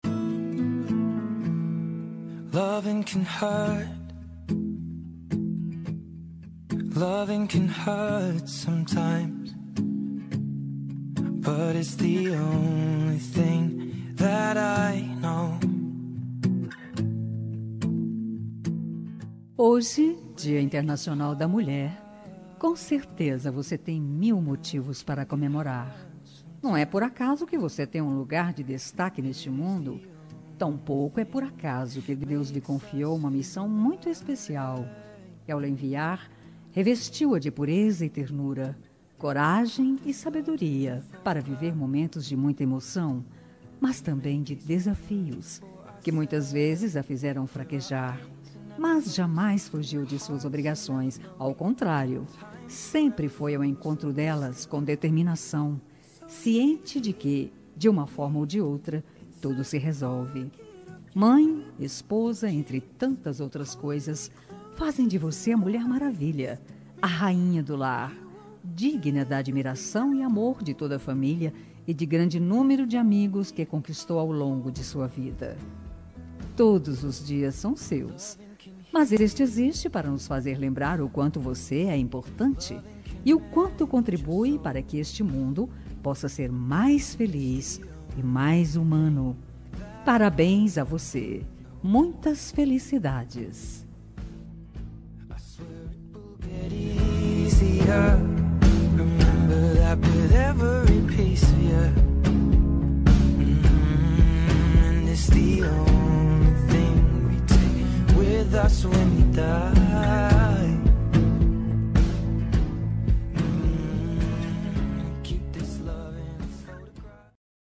Dia das Mulheres Neutra – Voz Feminina – Cód: 5289